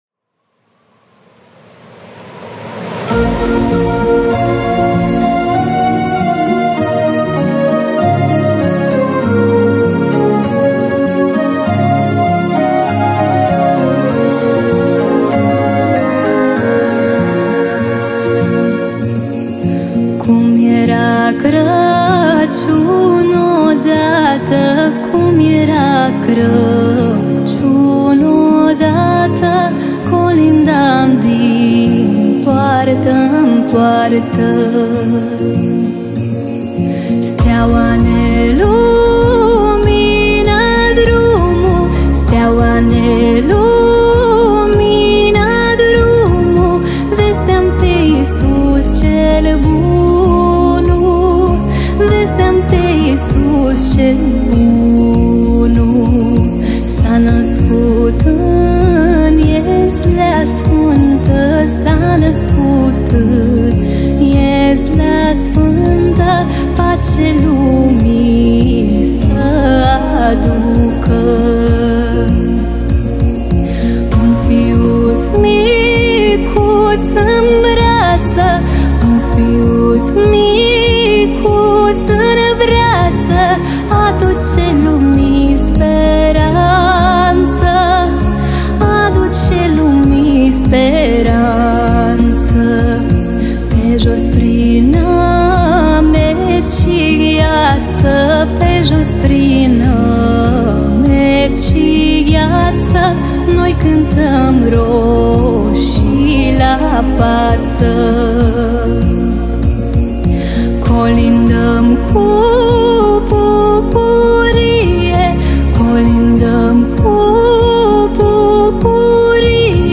румынская певица